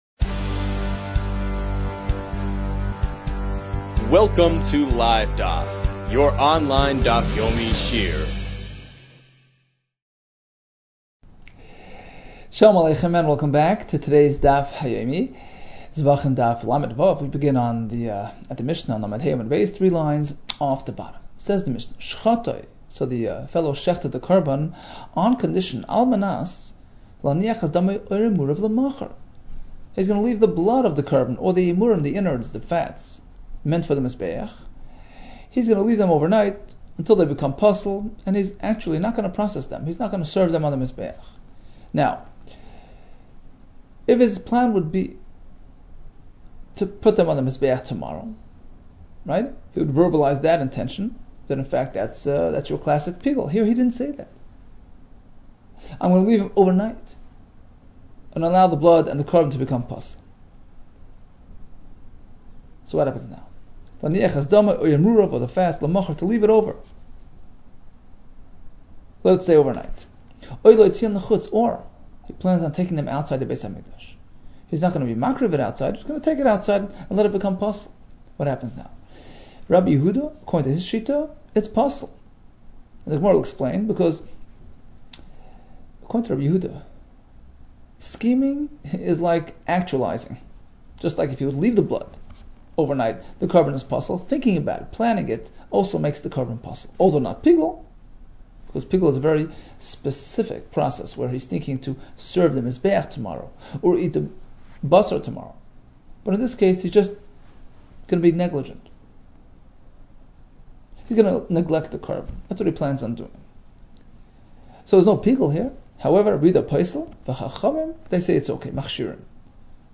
Zevachim 35 - זבחים לה | Daf Yomi Online Shiur | Livedaf